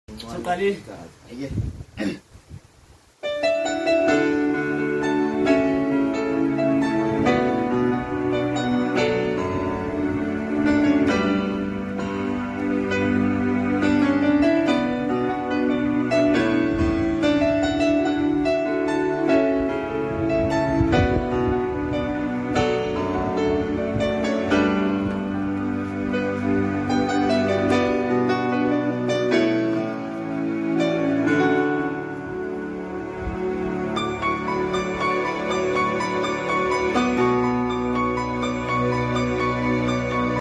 Keyboard player sound effects free download